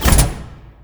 TOOL_Nailgun_02_mono edit.wav